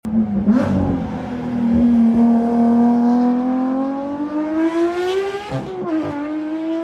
The Porsche 911 GT3 992. sound effects free download
Raw sound.